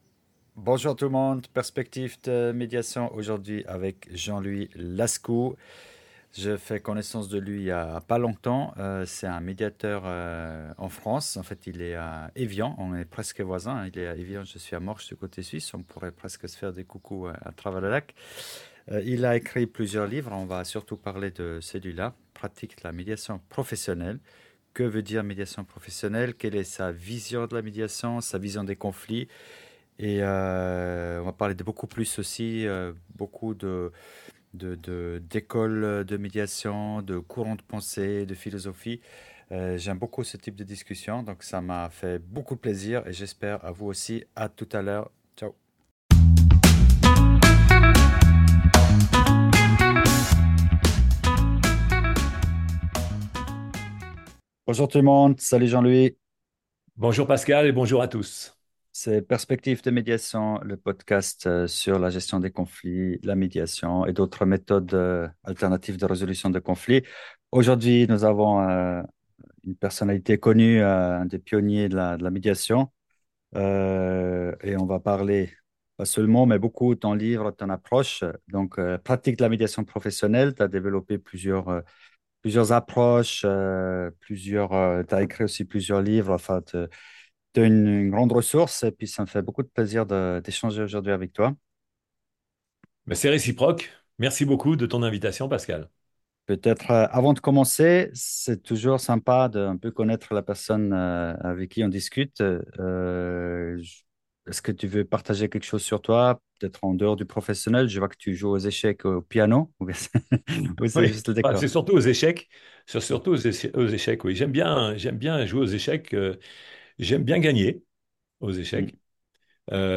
Une conversation enrichissante pour comprendre comment la médiation peut transf